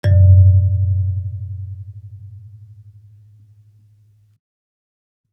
kalimba_bass-F#1-ff.wav